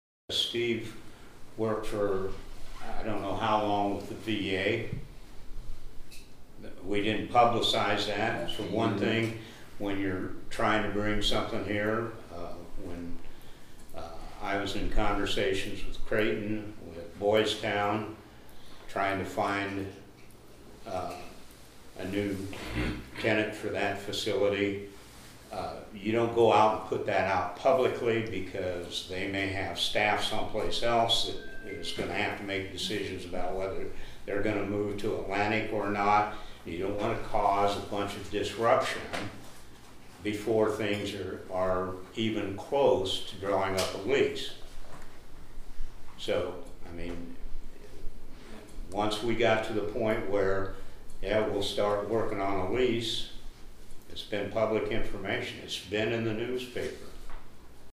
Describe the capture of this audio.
(Cass Co.) The lease agreement for the old Willow Heights residential facility was the main topic of discussion at the Cass County Board of Supervisors meeting again this morning.